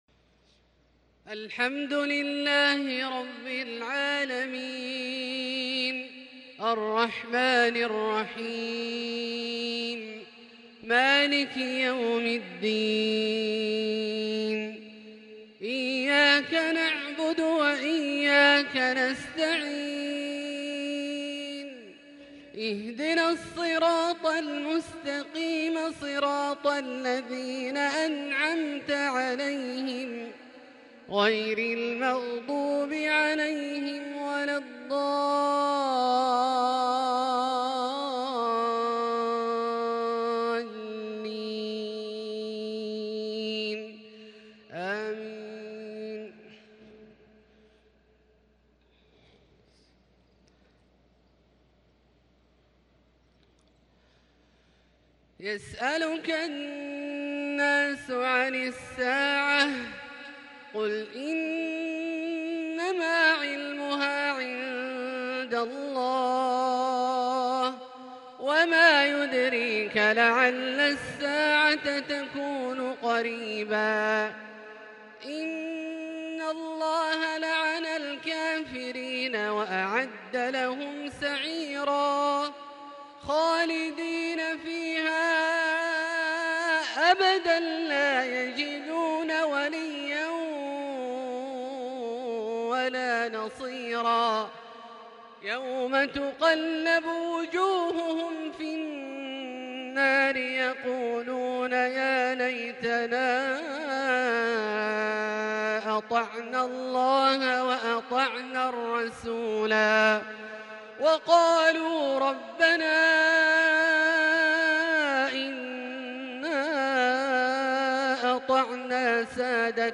عشاء الأحد 4 شوال 1442هــ أواخر سورة الأحزاب ( ٦٣-٧٣) > ١٤٤٢ هـ > الفروض - تلاوات عبدالله الجهني